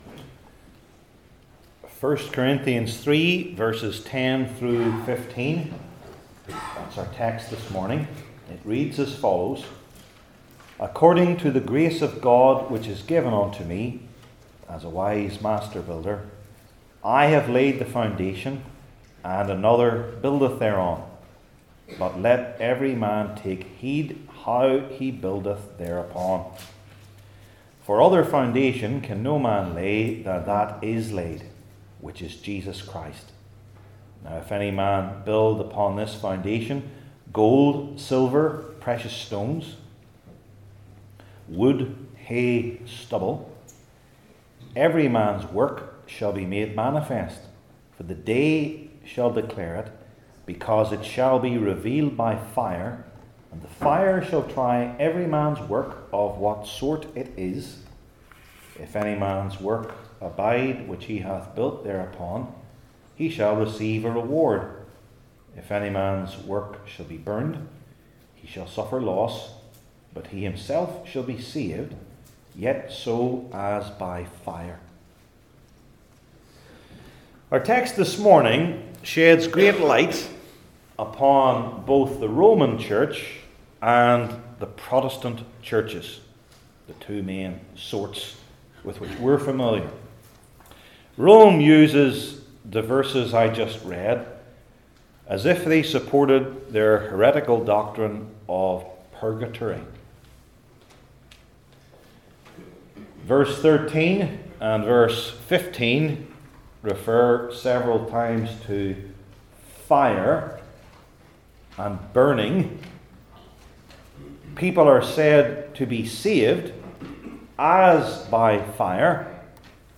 I Corinthians 3:10-15 Service Type: New Testament Sermon Series I. The False Interpretation II.